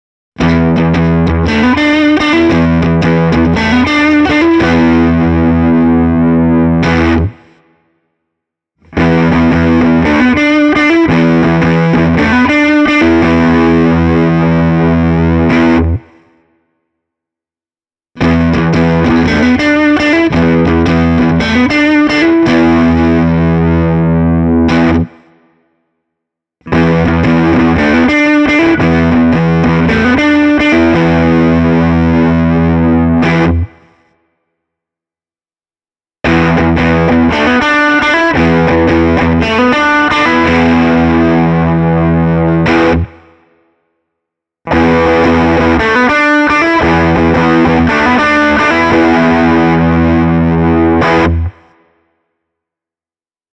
Lisäksi humbuckerien puolitus yksikelaisiksi lisää tutuille humbucker-soundeille vielä hyvin hyödylliset kevyemmät ja kirkkaammat vaihtoehdot.
Esimerkkipätkissä aloitan aina puolitetusta kaulamikrofonista, sen jälkeen tulee täysi kaulamikki ja niin edelleen:
ESP Eclipse II Distressed – säröllä
esp-eclipse-ii-distressed-e28093-overdrive.mp3